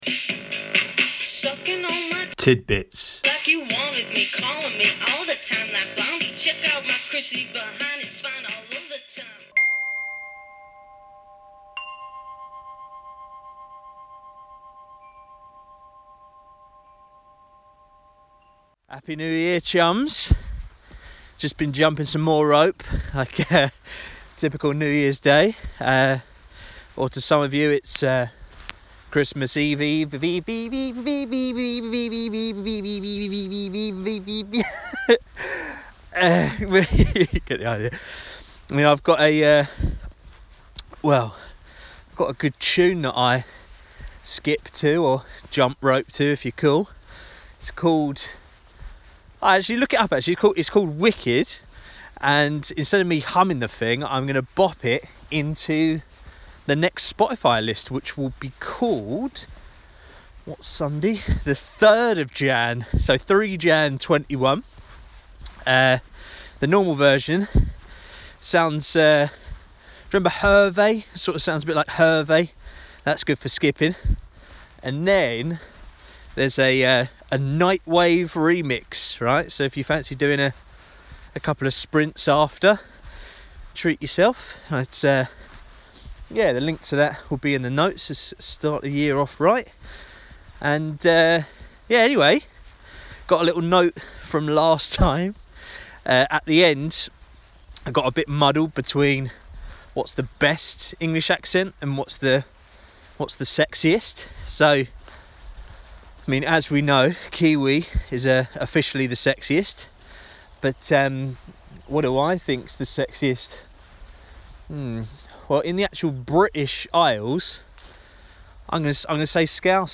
It's got a couple of clips from a catch-up